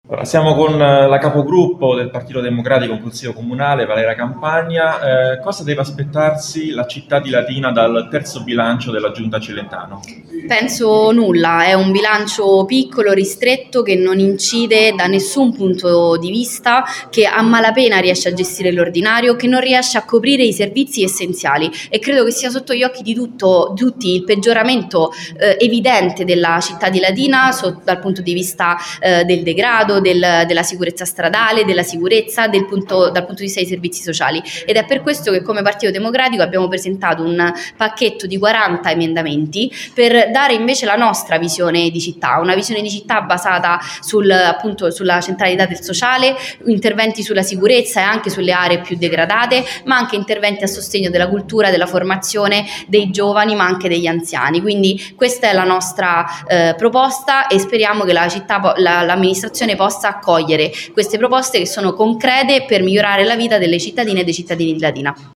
LATINA – Nella giornata di ieri si è tenuta la conferenza stampa di presentazione degli emendamenti che, in sede di bilancio previsionale, saranno presentati dal Partito Democratico di Latina. Sono 40 le proposte di integrazione da parte dei consiglieri Campagna e Majocchi.